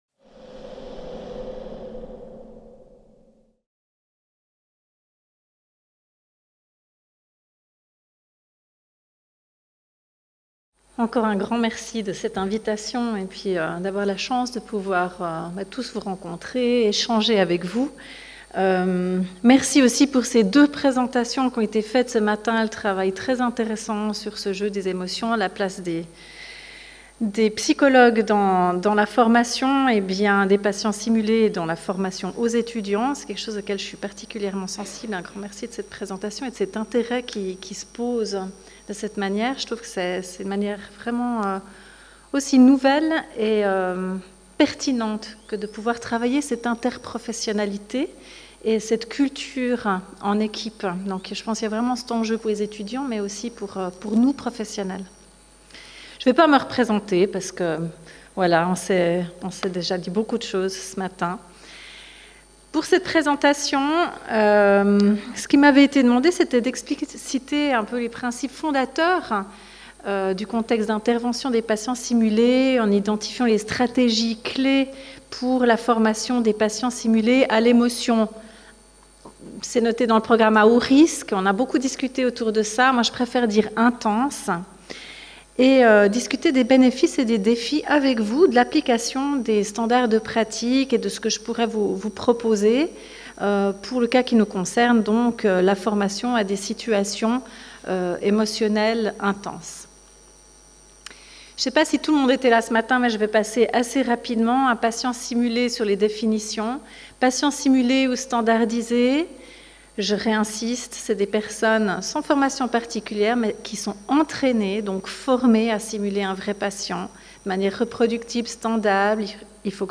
Congrès SoFraSimS Peut-on standardiser des situations émotionnelles à haut risque ?